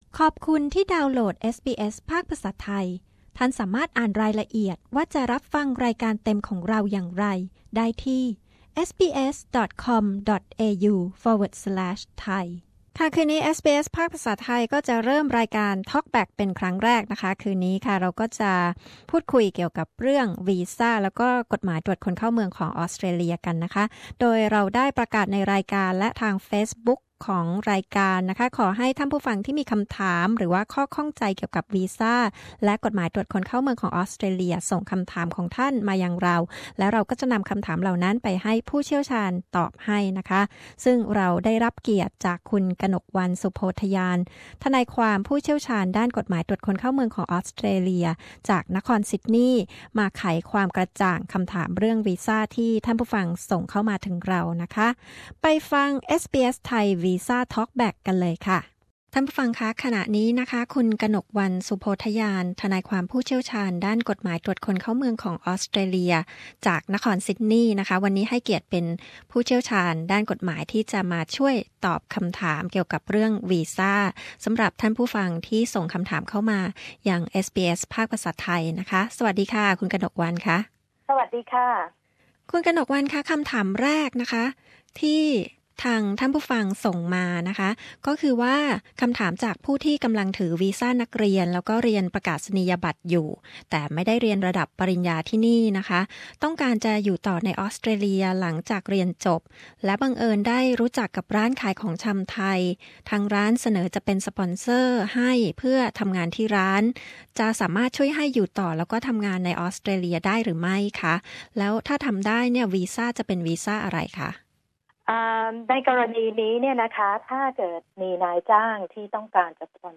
SBS Thai Visa Talkback